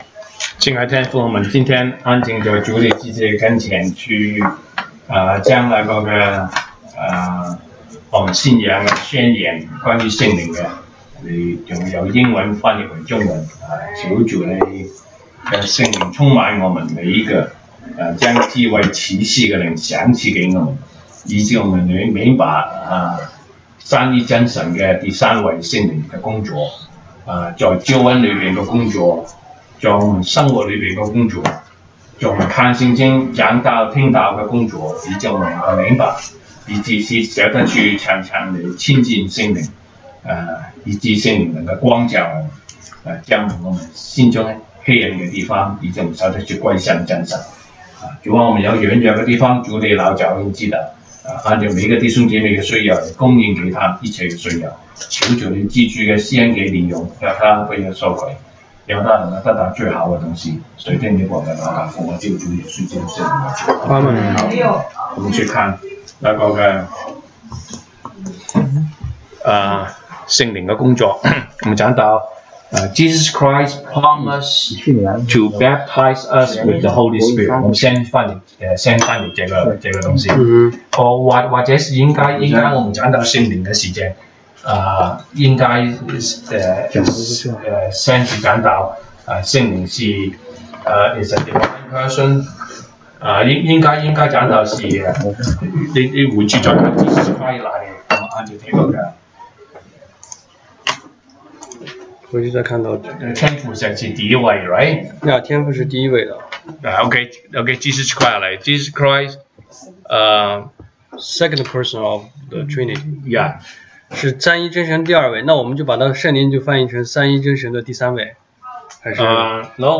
週一國語研經 Monday Bible Study « 東北堂證道